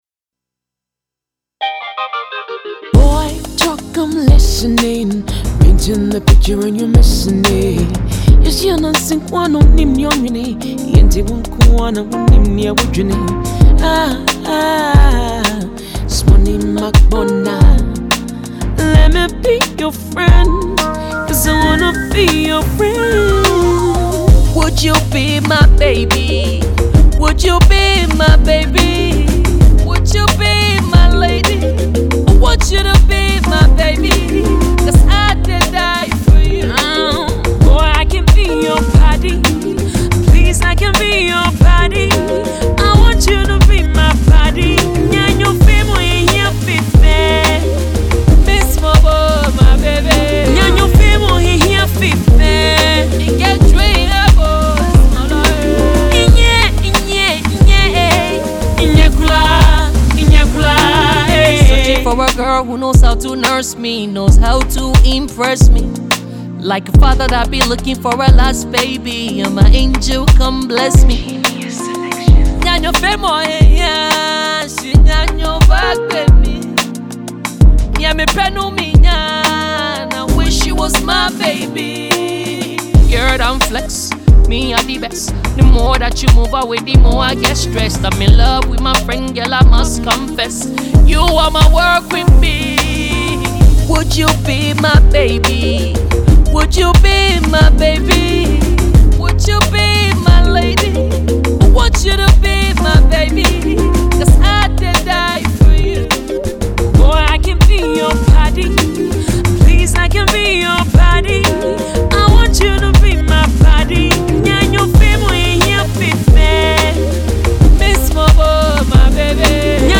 Ghanaian Rapper
Its A Smooth mellow Love song